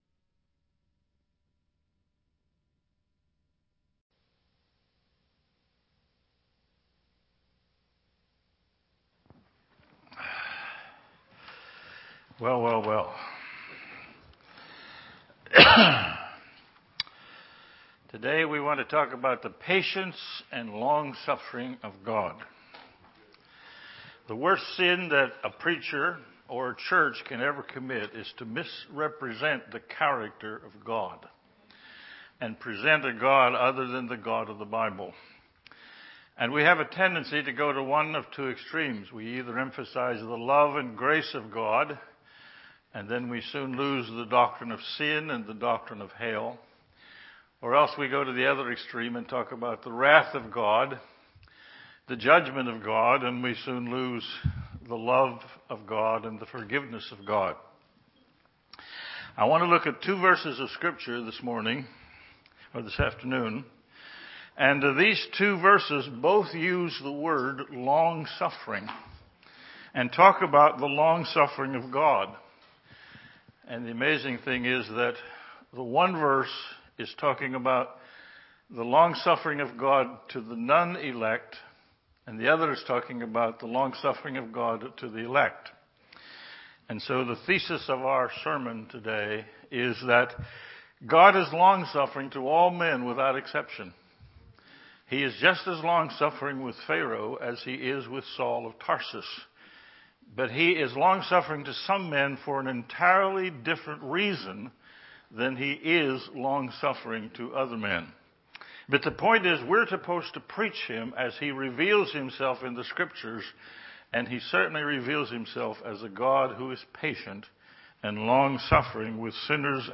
In this sermon, the preacher emphasizes the kindness and patience of God towards sinners. He uses the analogy of a person falling from a building, saying that sinners may think everything is fine, but it is only because of God's kindness. The preacher quotes Romans 2:4, highlighting that God's kindness leads to repentance.